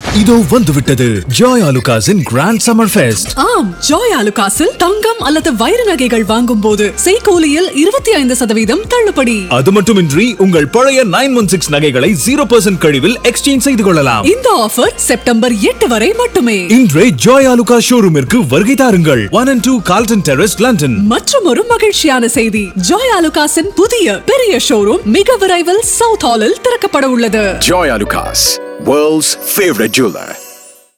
Joyalukkas – Radio Commercial